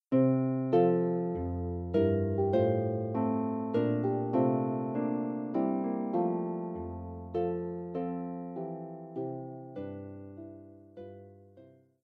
arranged for solo lever or pedal harp